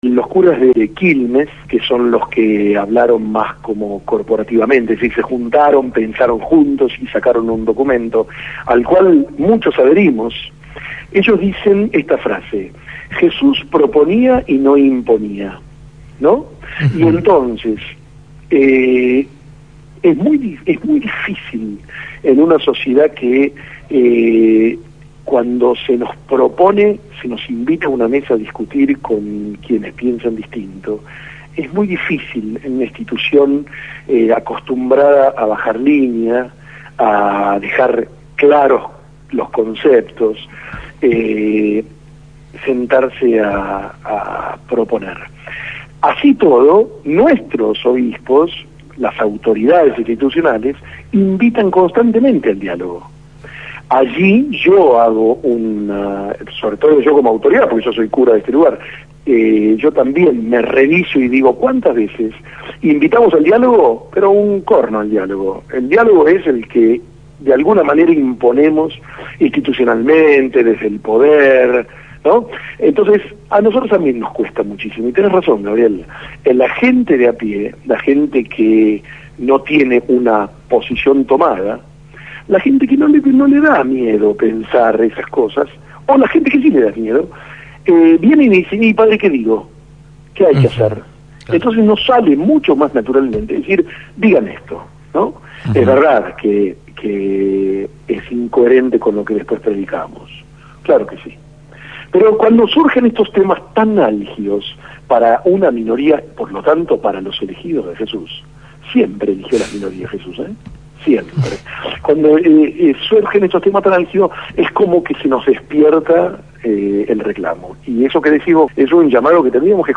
Sacerdote por la opción por los pobres se pronunció en la Gráfica